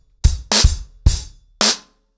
BlackMail-110BPM.9.wav